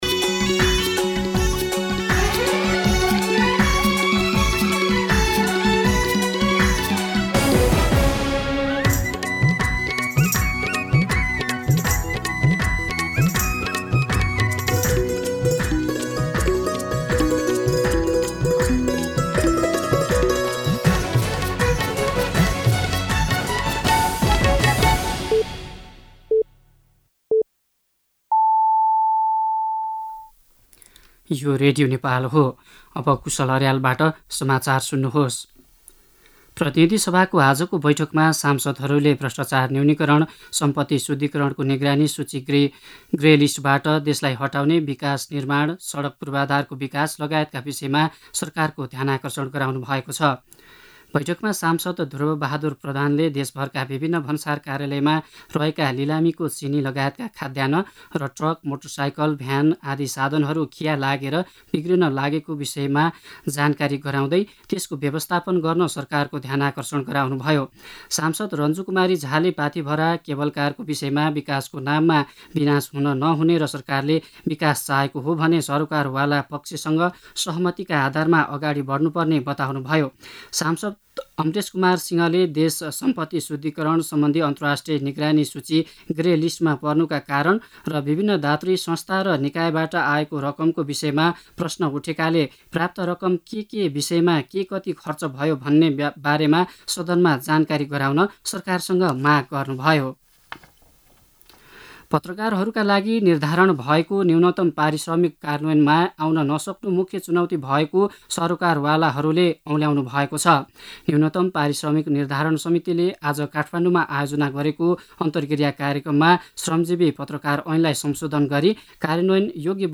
दिउँसो ४ बजेको नेपाली समाचार : १३ फागुन , २०८१
news-4-pm.mp3